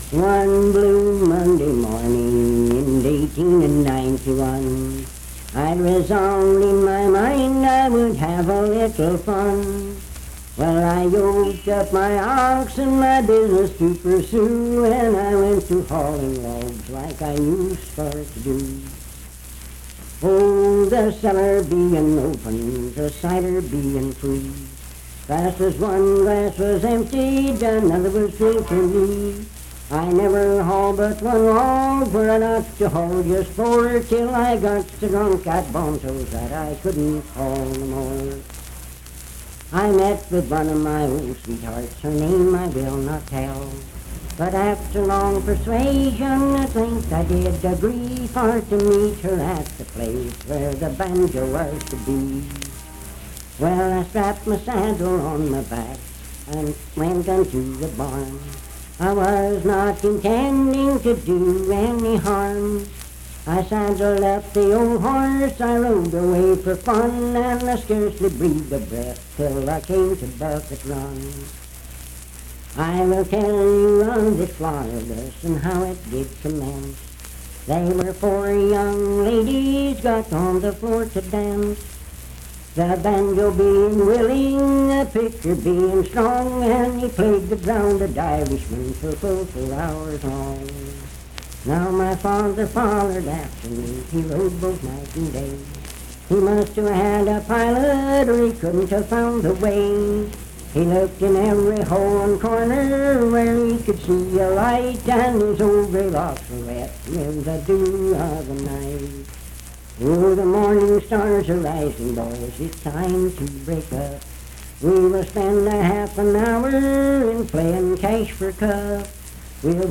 Unaccompanied vocal music
Verse-refrain 8d(4).
Performed in Sandyville, Jackson County, WV.
Voice (sung)